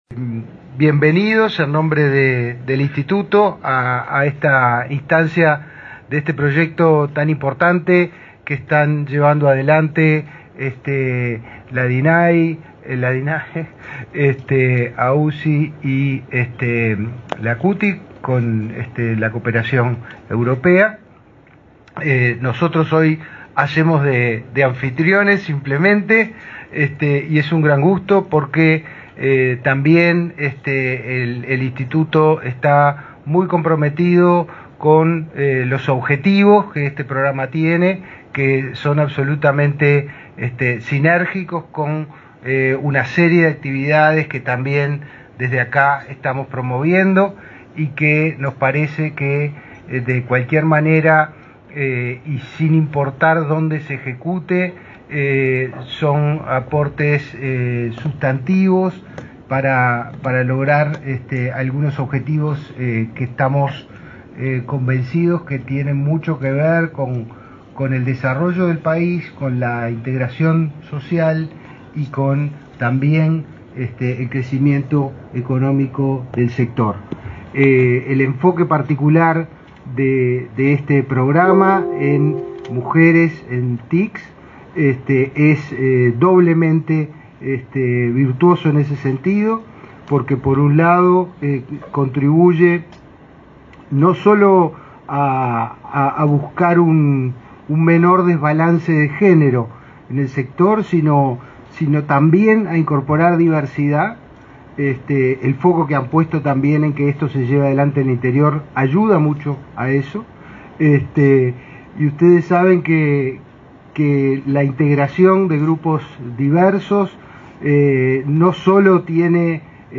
Palabras del director de Inefop y el ministro de Trabajo
Palabras del director de Inefop y el ministro de Trabajo 09/11/2022 Compartir Facebook X Copiar enlace WhatsApp LinkedIn El Ministerio de Trabajo participó con la prensa en un desayuno de trabajo a fin de informar acerca de un proyecto de ley para promover la incorporación de mujeres en el sector de las tecnologías de la información. El director del Instituto Nacional de Empleo y Formación Profesional (Inefop), Pablo Darscht, y el titular de la citada cartera, Pablo Mieres, señalaron la importancia de la temática.